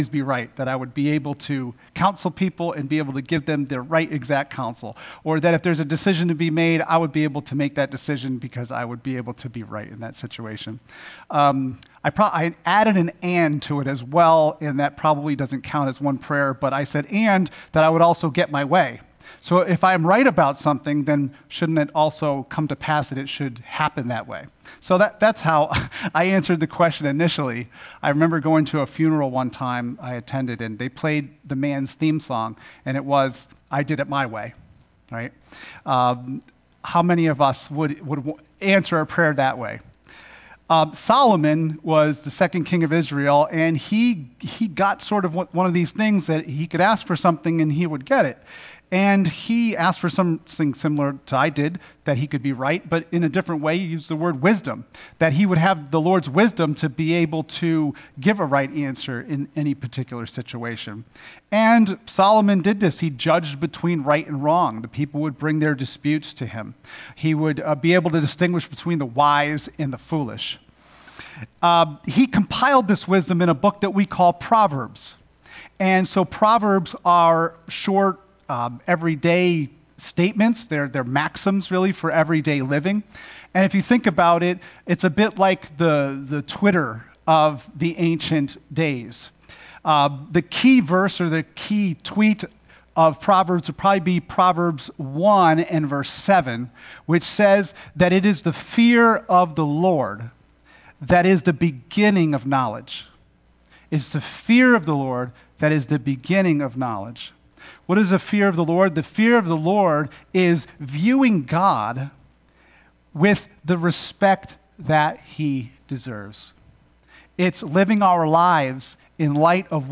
( Sunday AM )